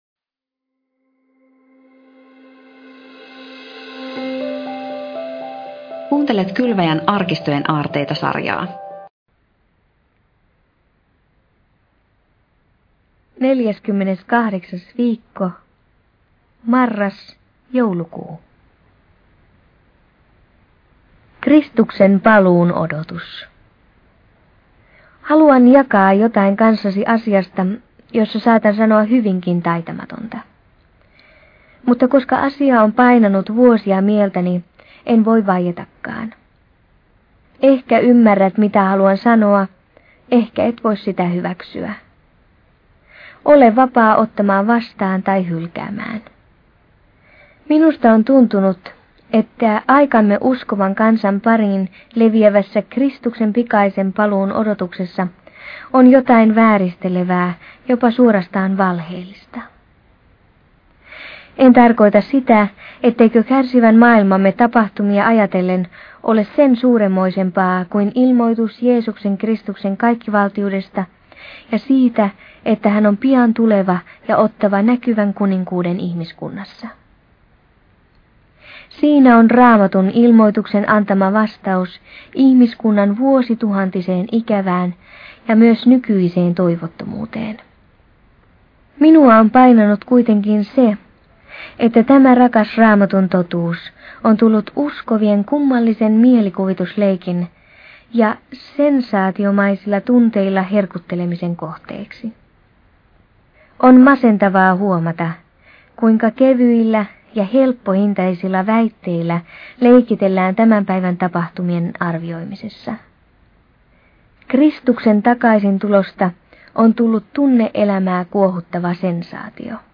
Lukija